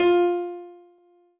piano-ff-45.wav